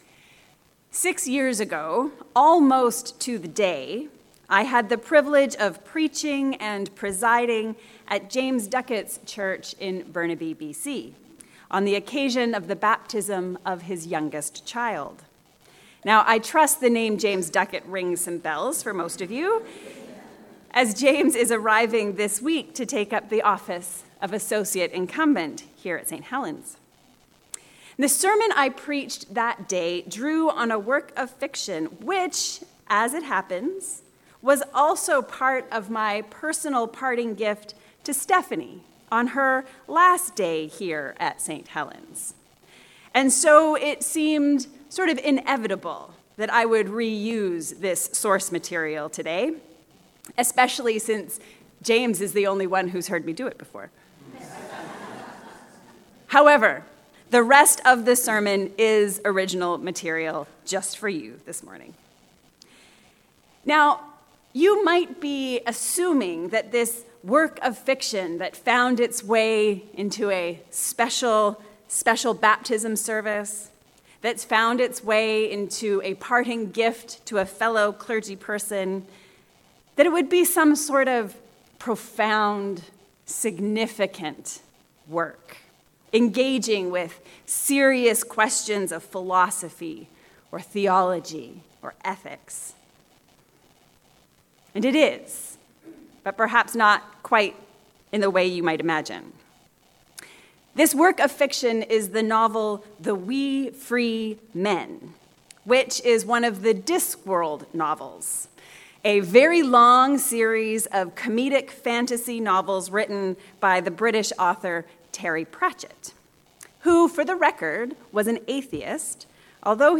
What do Christians and the Nac Mac Feegles have in common? A sermon on Colossians 3 and Luke 12